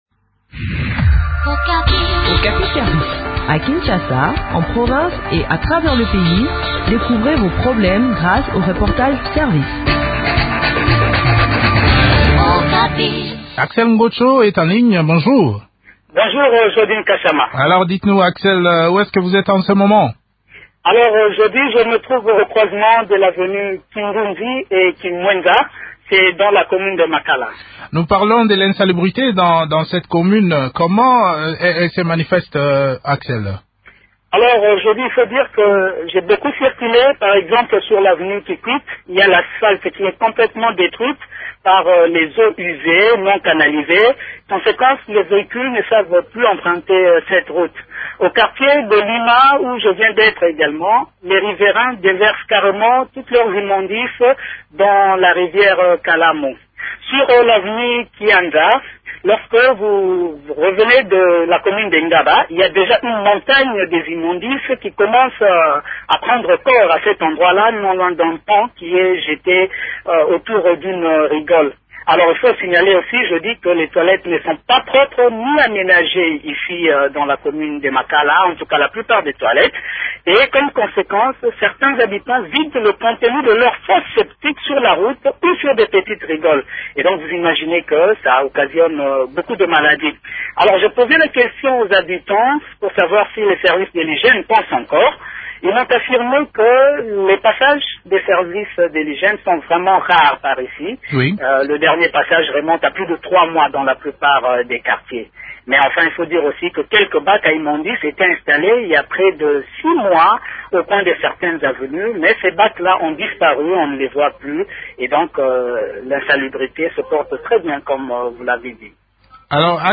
bourgmestre de la commune de Makala.